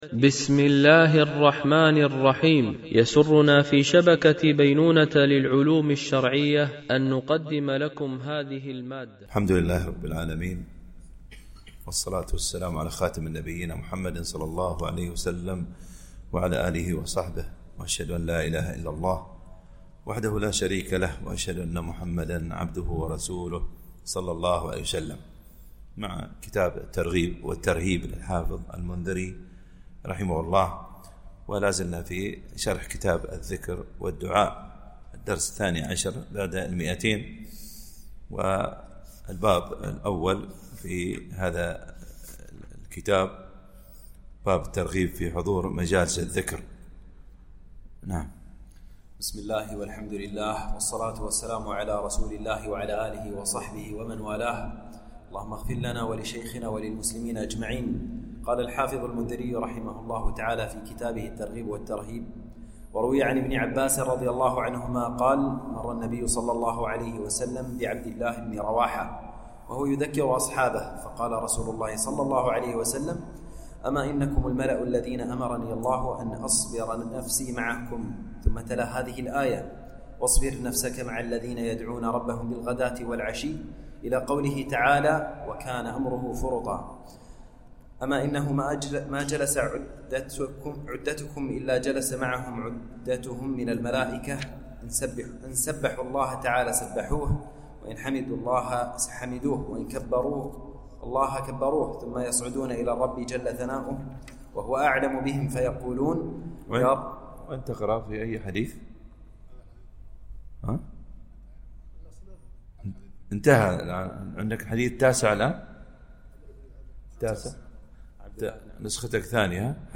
التنسيق: MP3 Mono 44kHz 64Kbps (CBR)